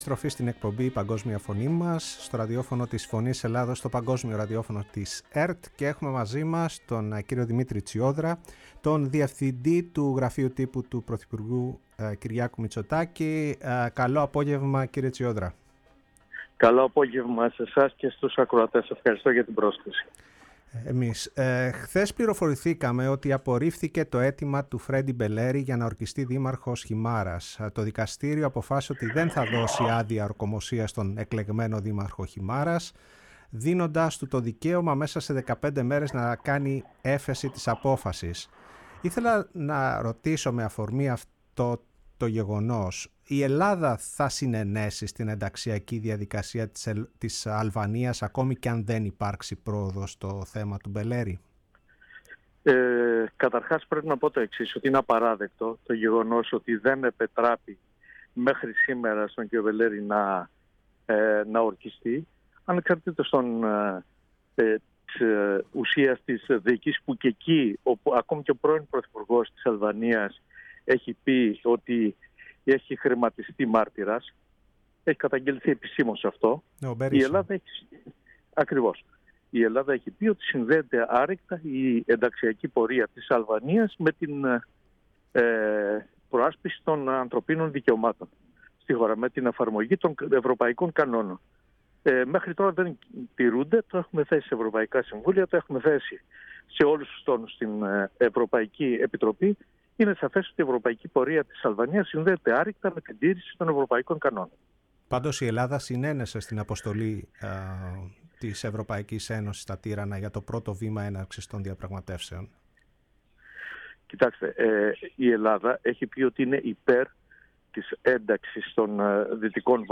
Η ΦΩΝΗ ΤΗΣ ΕΛΛΑΔΑΣ Η Παγκοσμια Φωνη μας ΣΥΝΕΝΤΕΥΞΕΙΣ Συνεντεύξεις ΔΗΜΗΤΡΗΣ ΤΣΙΟΔΡΑΣ επιστολικη ψηφος Ευρωεκλογες οπαδικη βια Φρεντυ Μπελερης